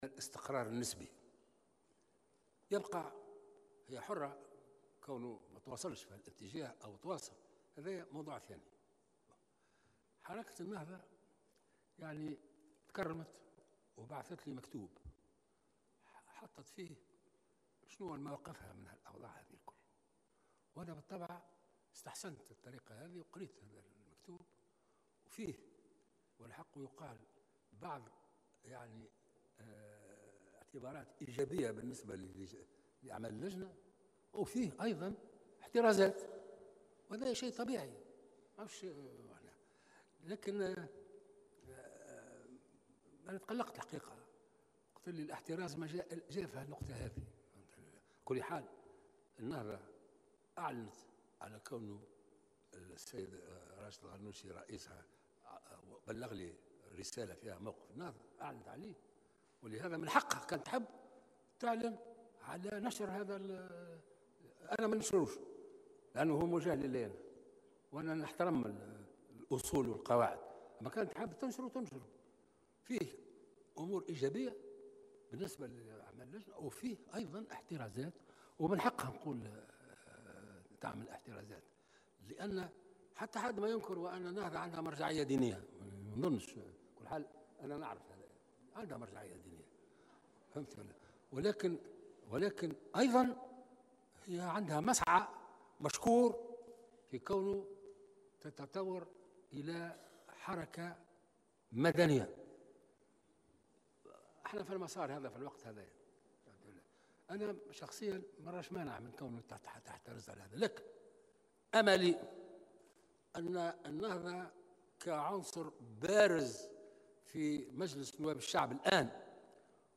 وأضاف رئيس الجمهورية أن الرسالة تضمنت موقف النهضة واساسا "احترزاتها" بشأن النقطة المتعلقة بالميراث، الأمر الذي أثار قلقه، بحسب تعبيره على هامش كلمة ألقاها بمناسبة عيد المرأة التونسية.